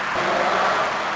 I received that recording on 2 different recorders, over 50 feet apart in a huge mansion, in 2004.
If you have a multi-voice EVP, that is screaming "I can hear us!"  it is pretty tough to dispute.